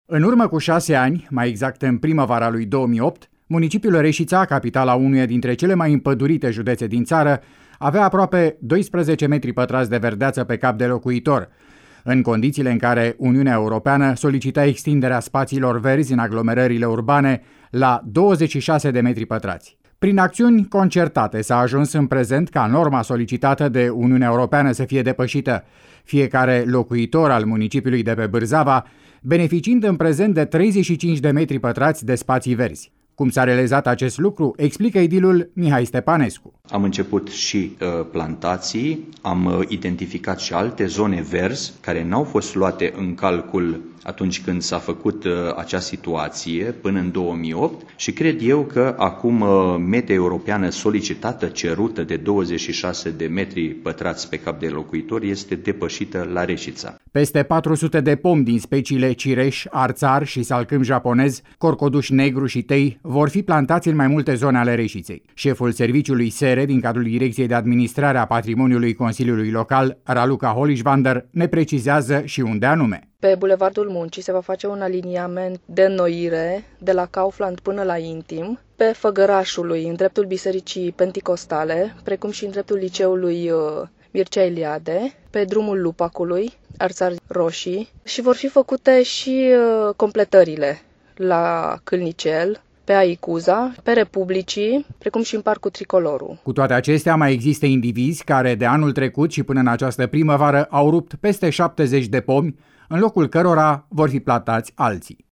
Cum s-a realizat acest lucru explică edilul Mihai Stepanescu: